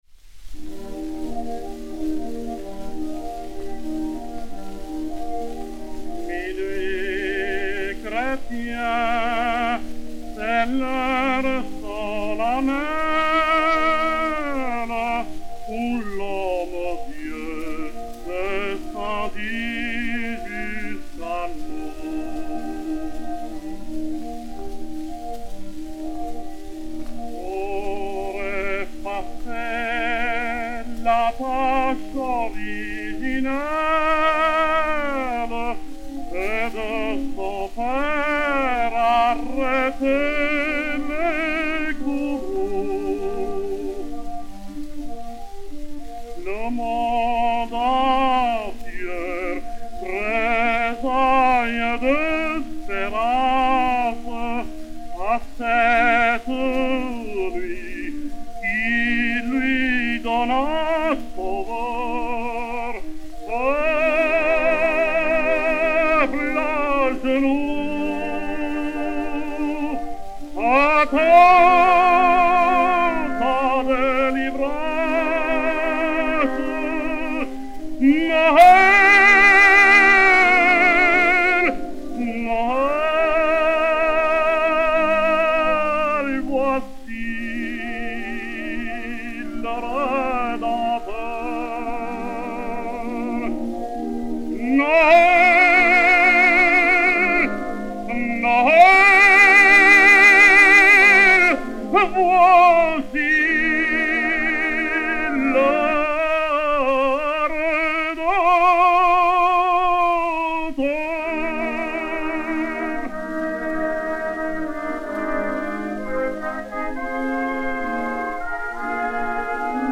Enrico Caruso et Orchestre dir. Walter B. Rogers
C-17218, réédité sur Gramophone DB 139, mat. 2-32022, enr. à Camden, New Jersey, le 23 février 1916